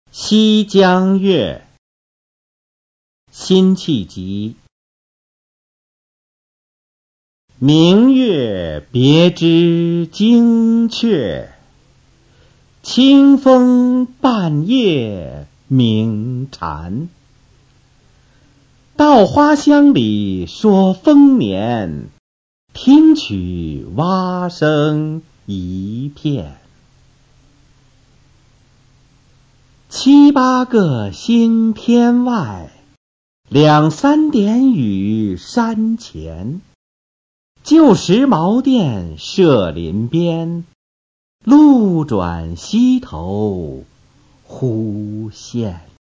《西江月·明月别枝惊鹊》原文和译文（含赏析、朗读）　/ 辛弃疾